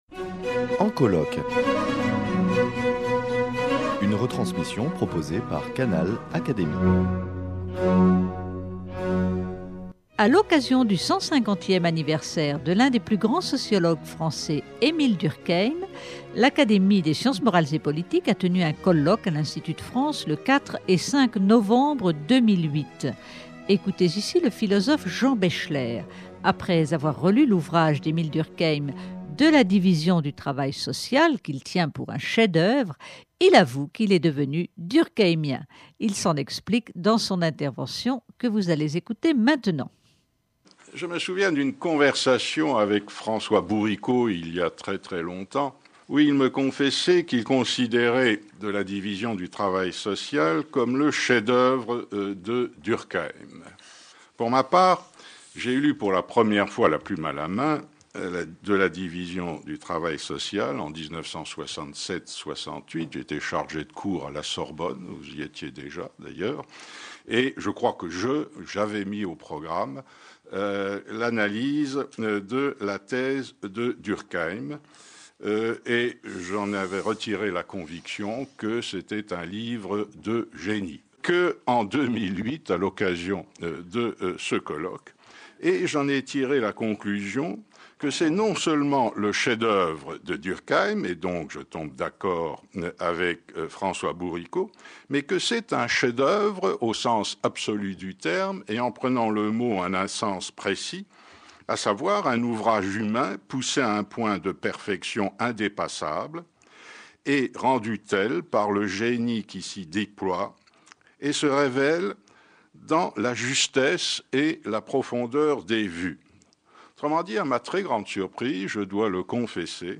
Il s’en explique dans cette intervention brillante.